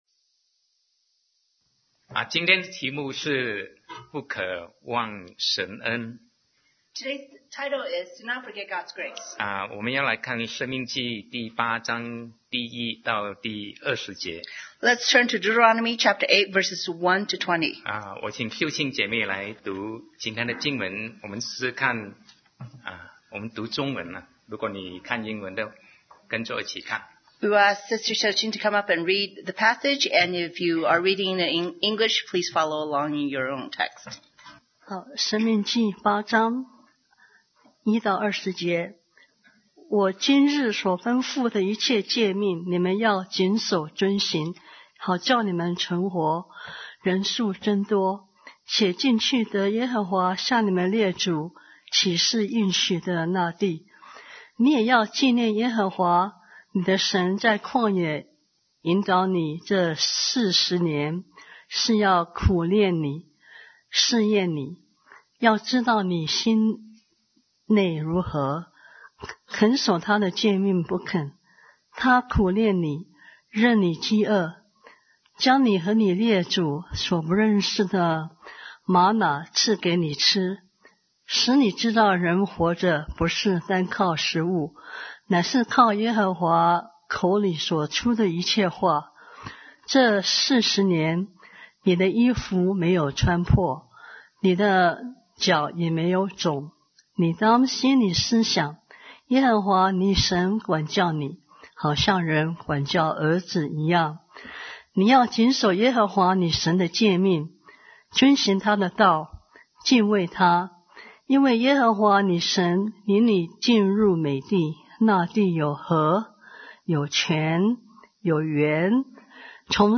Sermon 2019-03-10 Do Not Forget God’s Grace